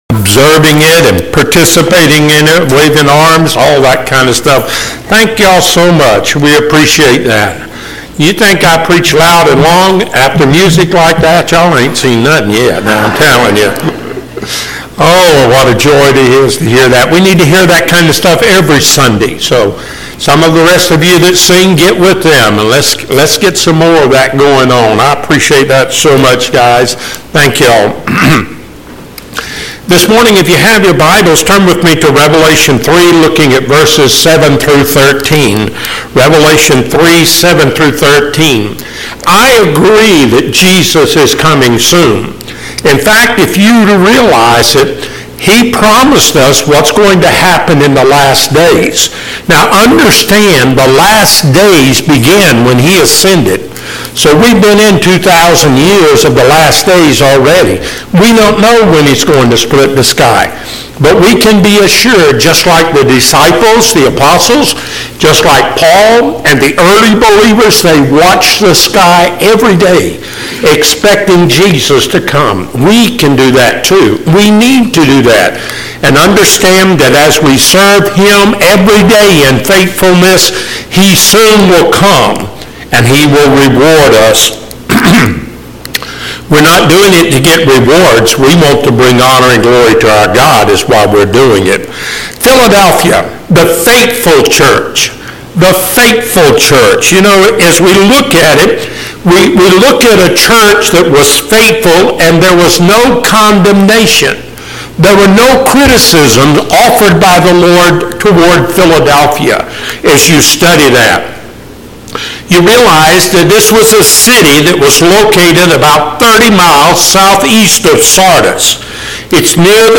7 Churches in Revelation Passage: Revelation 3:7-13 Service Type: Sunday Morning Topics